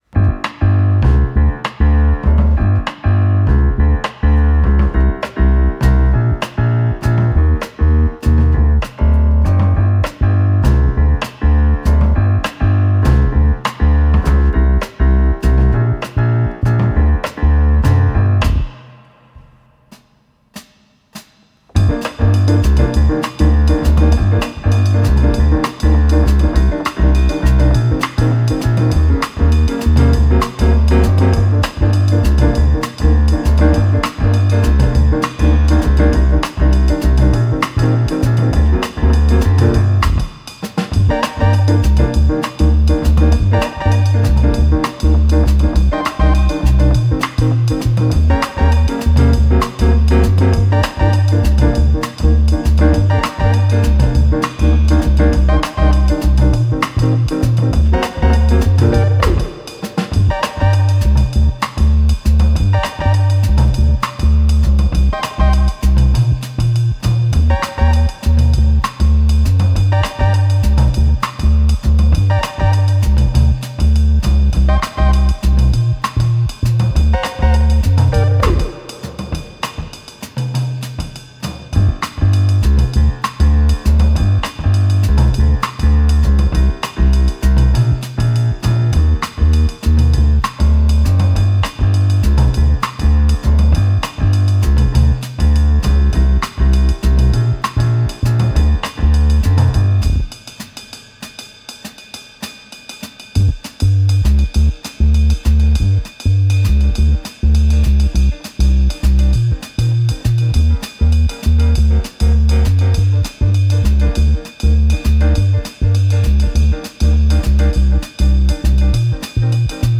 Uptempo latin jazz with a mysterious vibe.